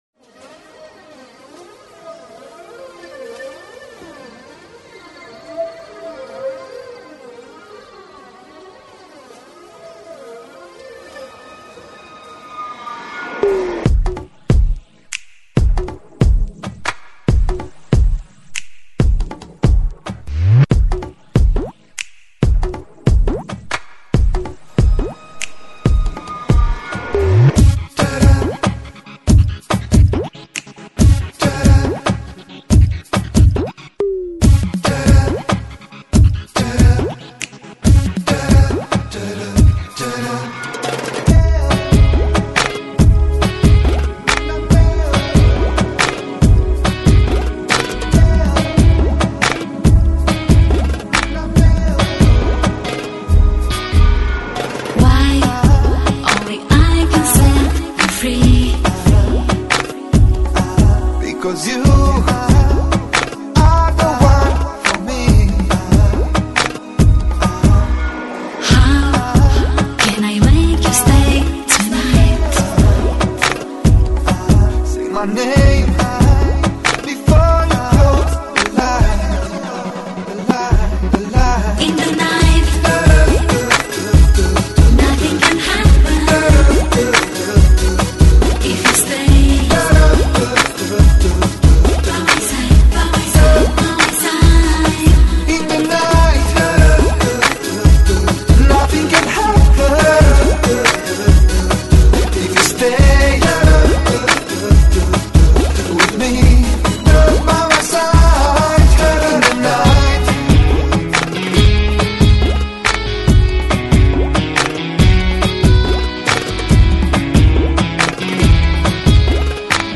罗马尼亚女歌手
Genre: Pop/Dance